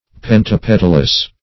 Search Result for " pentapetalous" : The Collaborative International Dictionary of English v.0.48: Pentapetalous \Pen`ta*pet"al*ous\, a. [Penta- + petal.]
pentapetalous.mp3